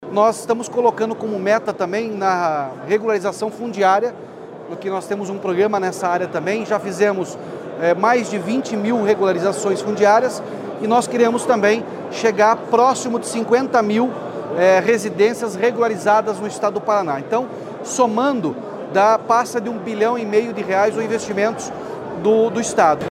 Sonora do governador Ratinho Junior sobre o anúncio de R$ 100 milhões para apoiar os municípios paranaenses na regularização fundiária